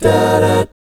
1-ABMI7   -R.wav